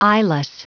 Prononciation du mot eyeless en anglais (fichier audio)
Prononciation du mot : eyeless